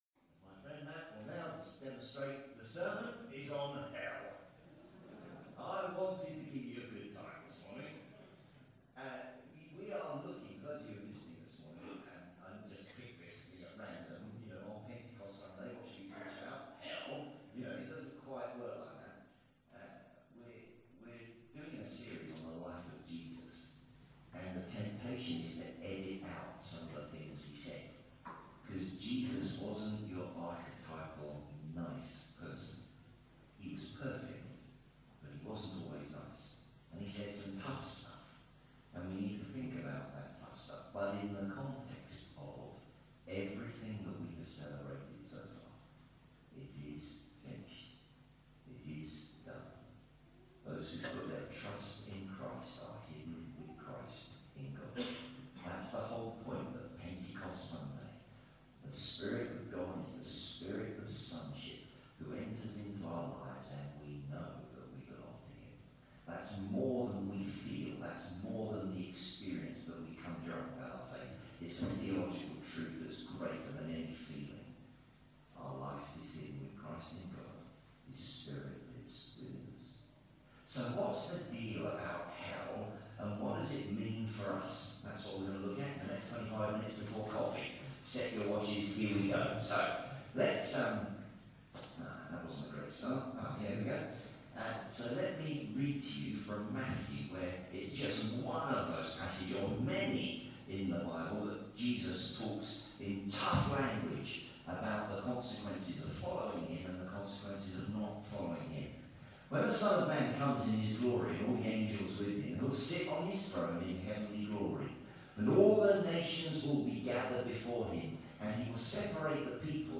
A hell of a sermon! (Matthew 25)